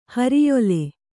♪ hariyole